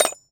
metal_small_movement_11.wav